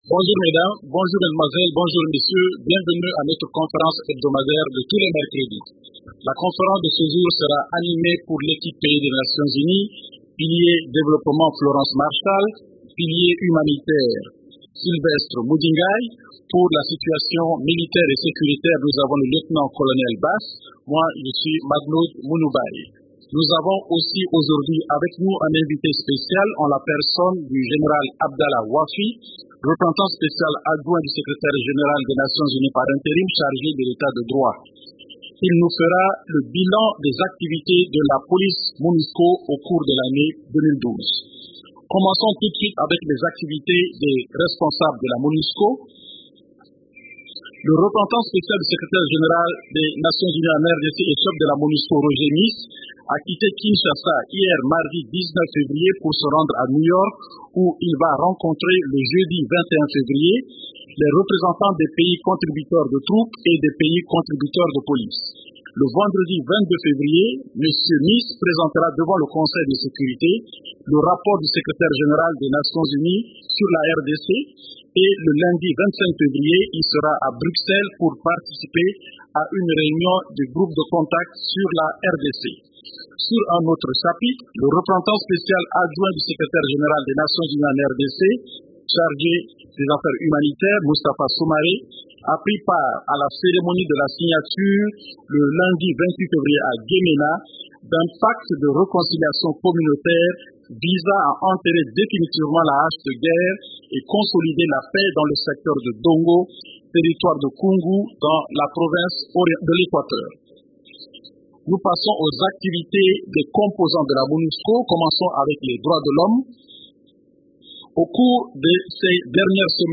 Conférence du 20 février 2013
La conférence hebdomadaire des Nations unies du mercredi 13 février a porté sur les sujets suivants:
Voici le verbatim de cette conférence de presse.